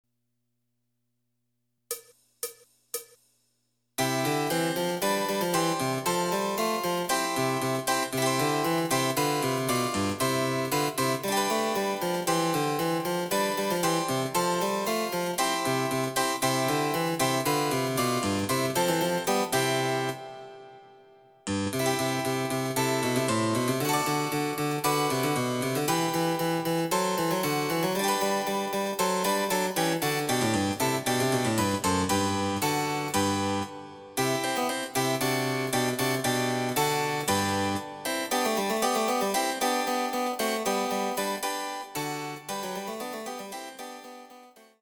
その他の伴奏
Electoric Harpsichord